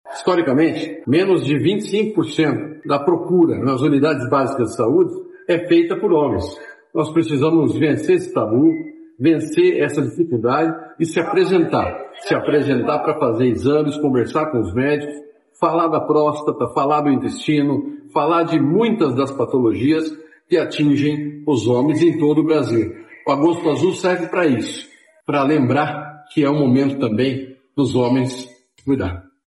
Sonora do secretário da Saúde, Beto Preto, sobre o Agosto Azul